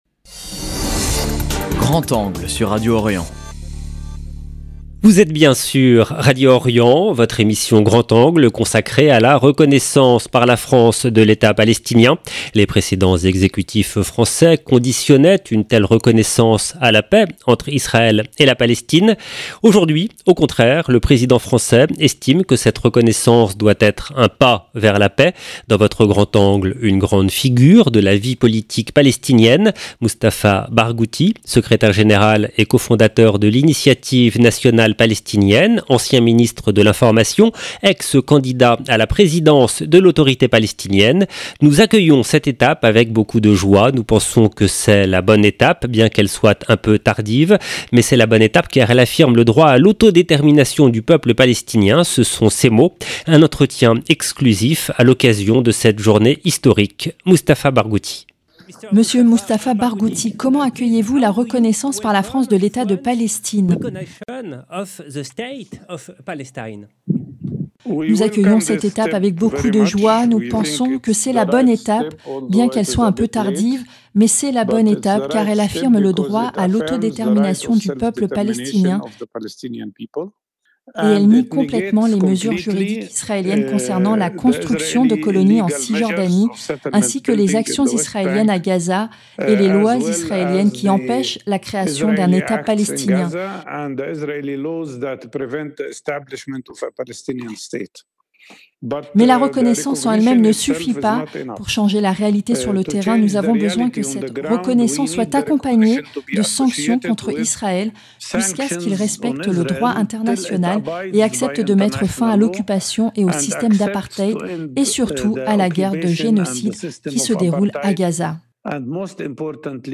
Un entretien exclusif à l’occasion de cette journée historique, Mustafa Barghouti : 0:00 9 min 43 sec